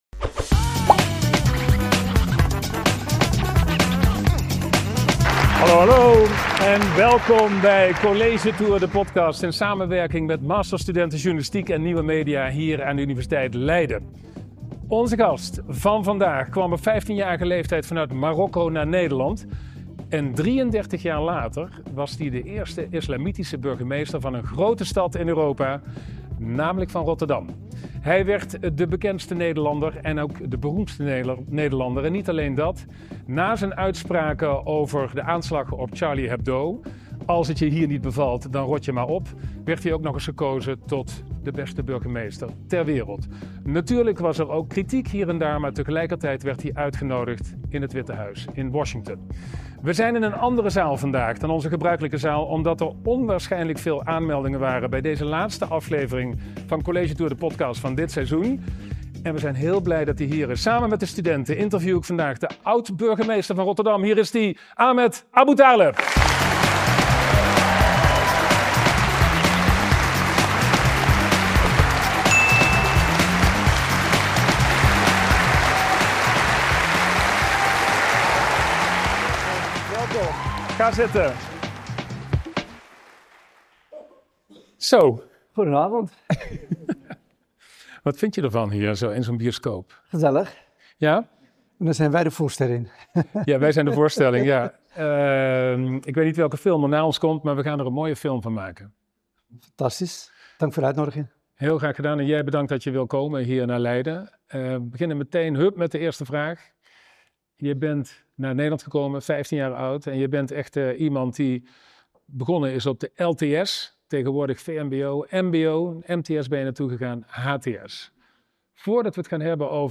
In de laatste aflevering van dit seizoen van College Tour de podcast! schuift oud-burgemeester Ahmed Aboutaleb aan bij Twan Huys voor een extra feestelijke opname in een volle zaal van het Trianon Theater in Leiden.